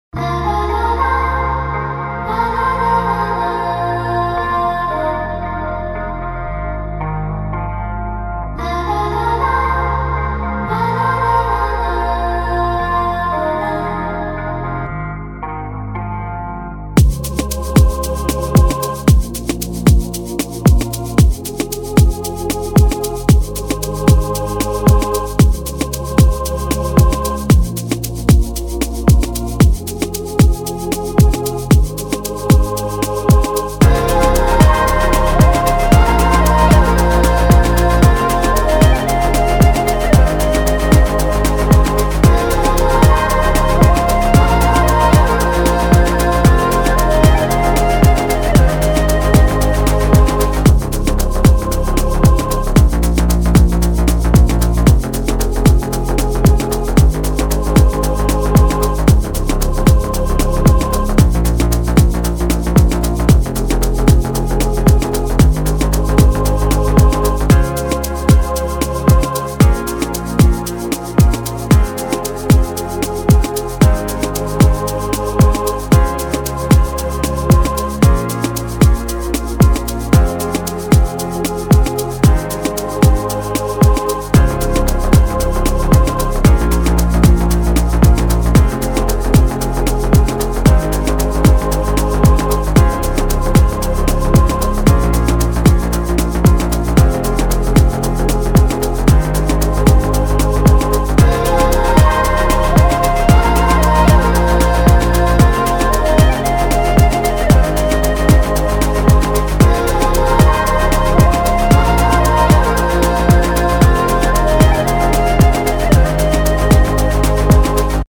This is the official instrumental
2025 in Dancehall/Afrobeats Instrumentals